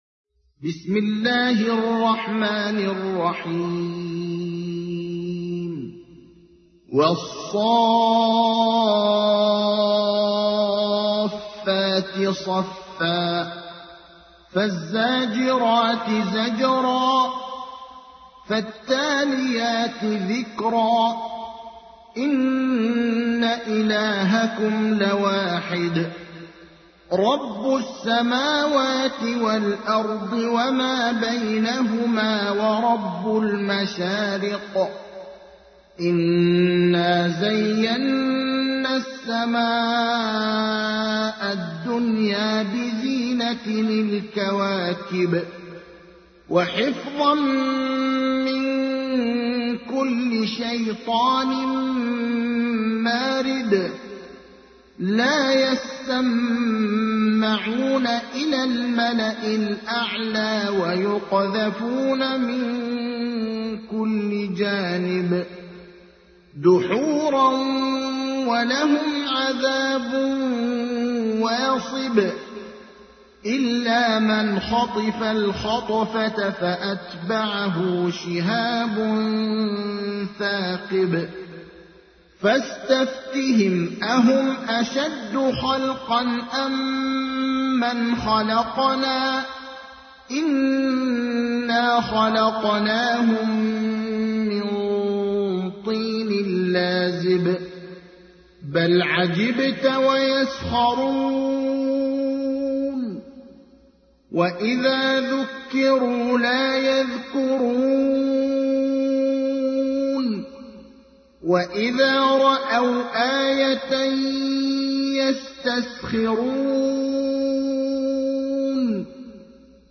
تحميل : 37. سورة الصافات / القارئ ابراهيم الأخضر / القرآن الكريم / موقع يا حسين